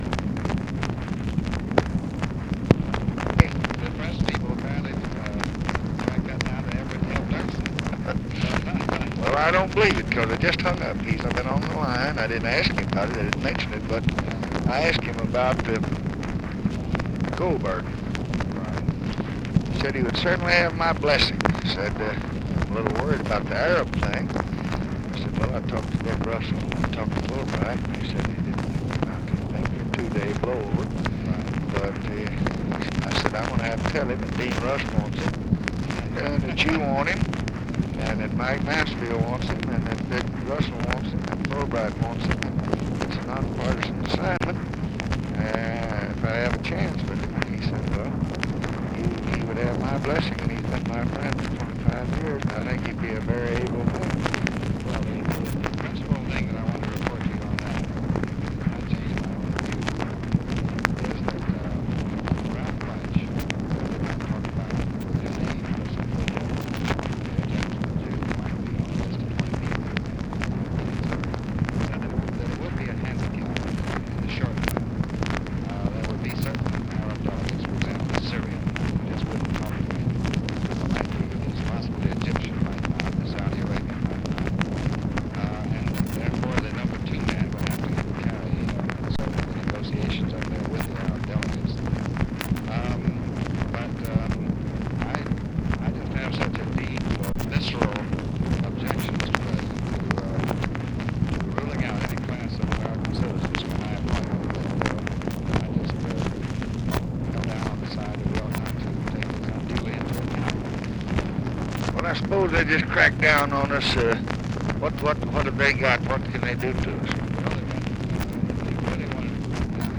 Conversation with DEAN RUSK, July 20, 1965
Secret White House Tapes